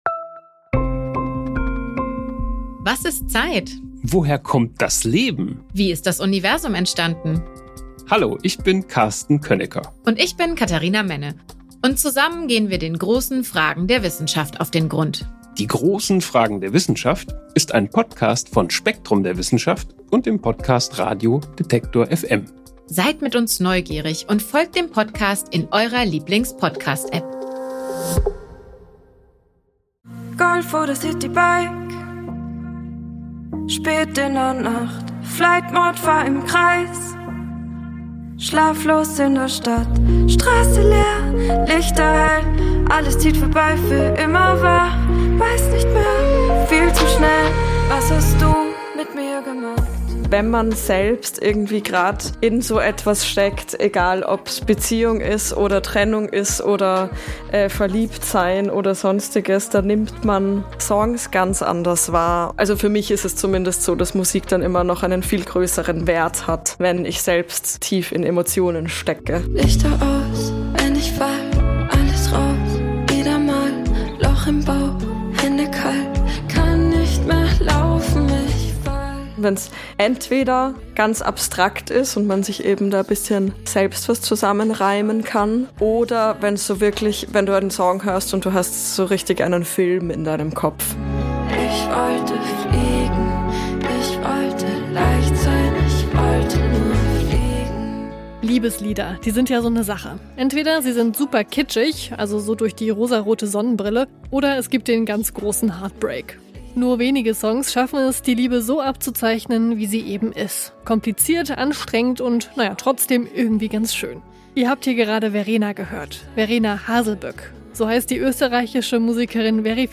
Diese Woche übernimmt sie den Popfilter und stellt Songs über echte Liebe vor. Denn die hat viele Zwischentöne und klingt nicht nur nach rosaroter Brille.